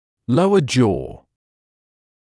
[‘ləuə ʤɔː][‘лоуэ джоː]нижняя челюсть